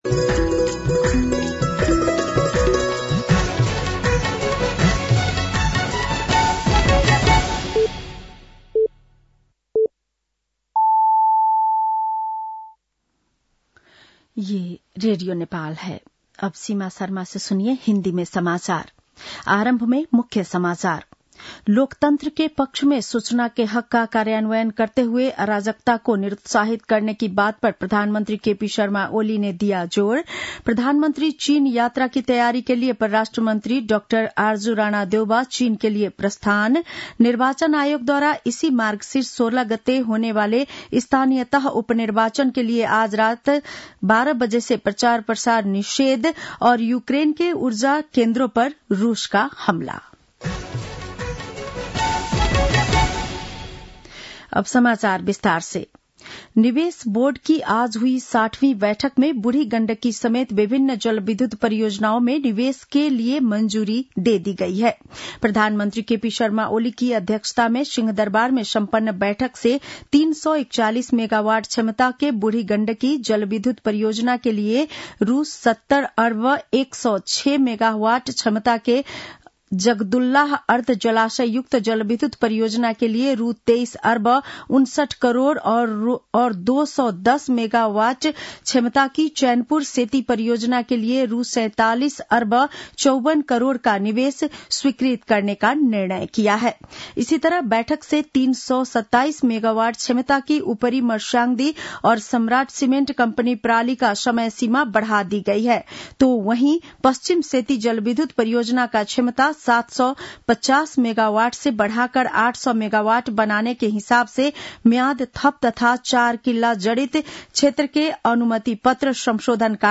बेलुकी १० बजेको हिन्दी समाचार : १४ मंसिर , २०८१